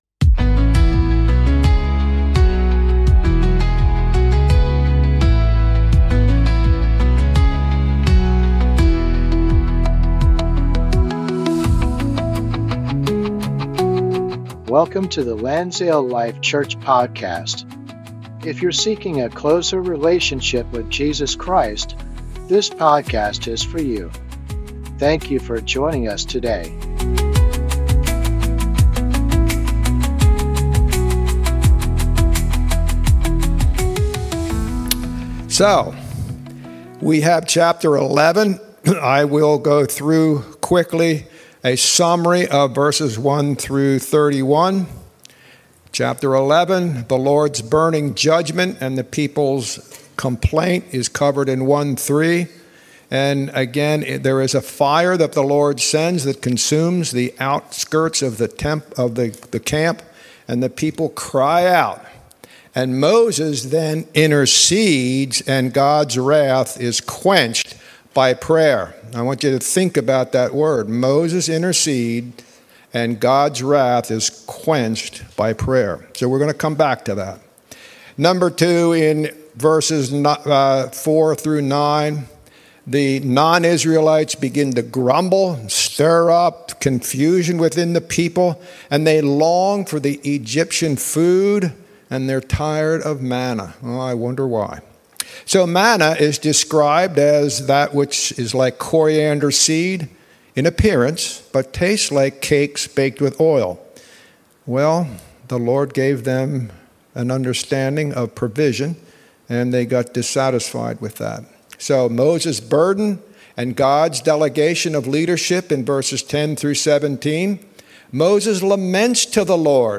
An ongoing bible study on the book of Numbers.